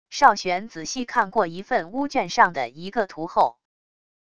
邵玄仔细看过一份巫卷上的一个图后wav音频生成系统WAV Audio Player